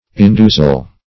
Indusial \In*du"sial\, a. [See Indusium.]